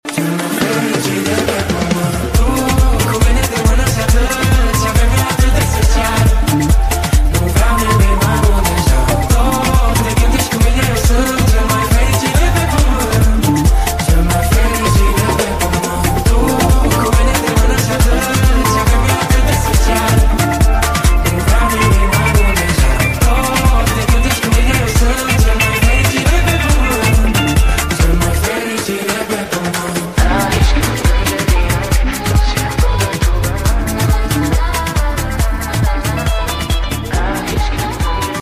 DANCE FITNESS CLASS in Cluj-Napoca